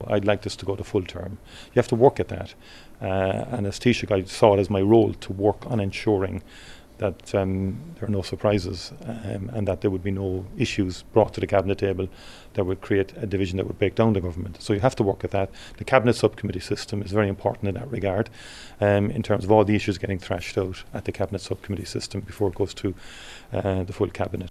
Outgoing Taoiseach Micheál Martin says a lot of work has gone into maintaining good relationships in the coalition: